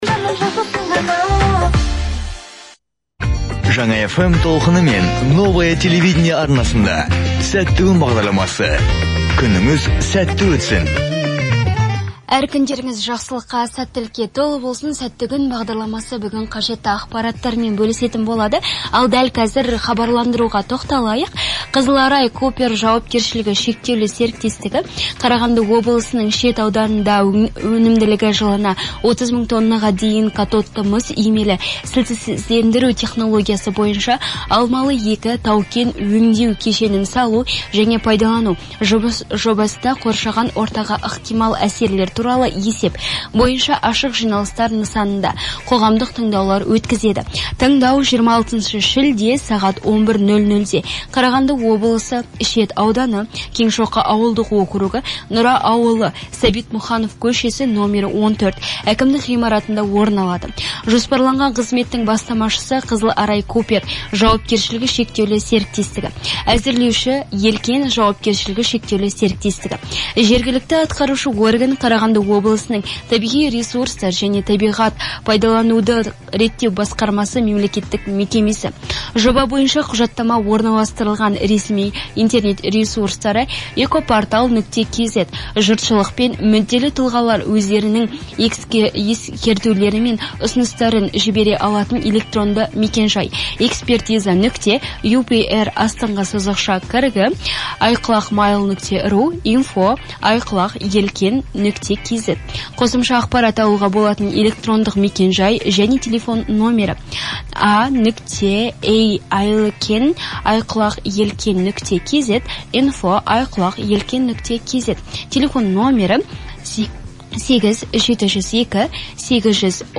Подтверждающий документ о своевременном размещении объявления о проведении общественных слушаний не менее чем в одном теле- или радиоканале